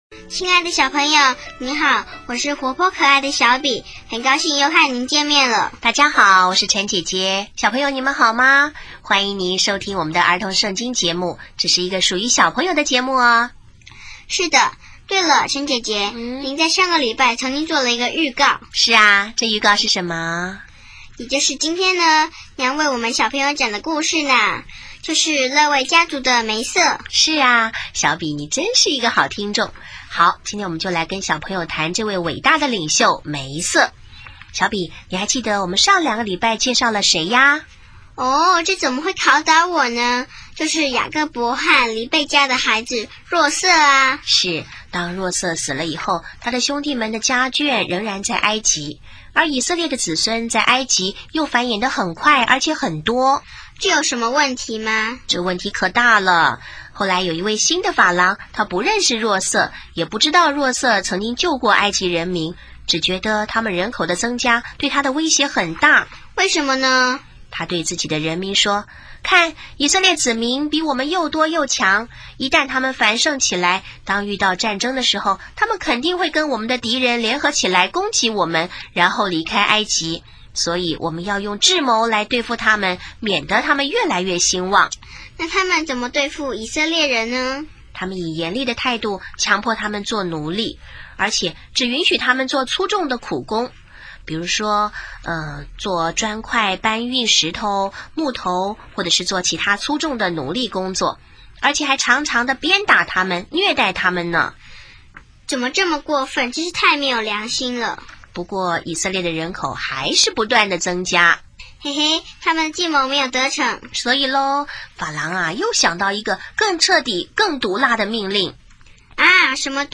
【儿童圣经故事】14|梅瑟(一)渡红海出埃及